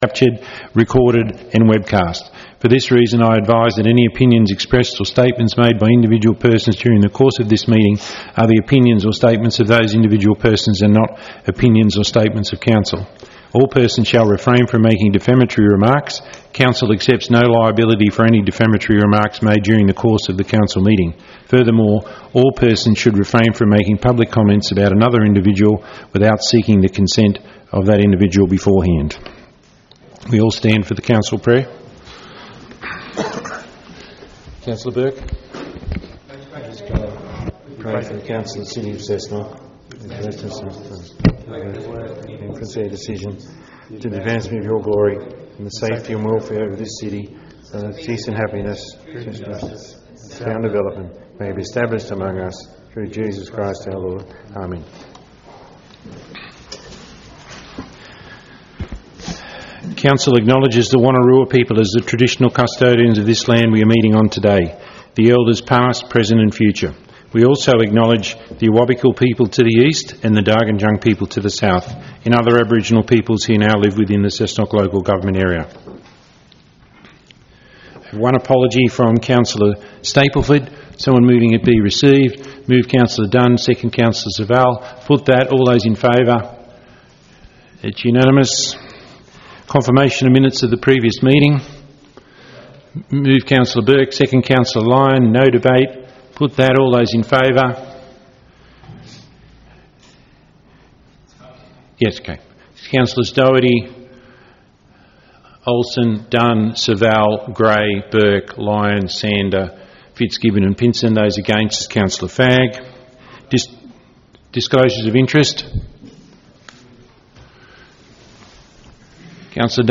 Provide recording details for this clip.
Meetings are held in the Council Chambers, 62-78 Vincent Street, Cessnock.